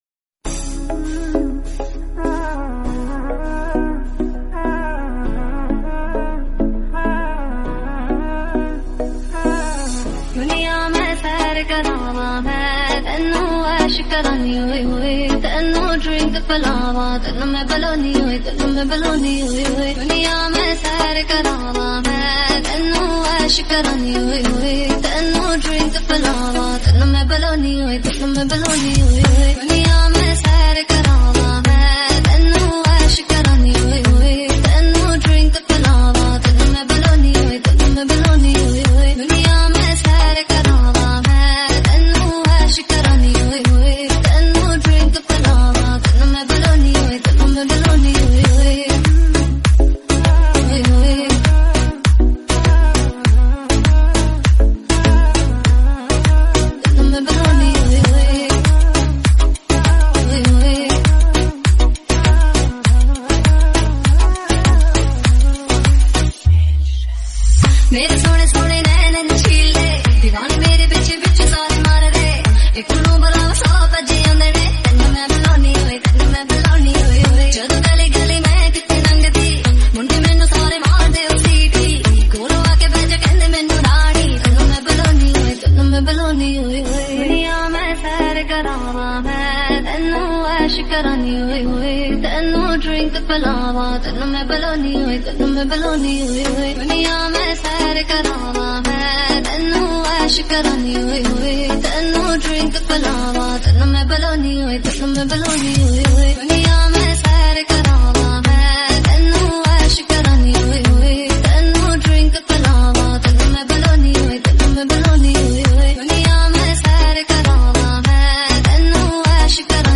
punjabi music bass boosted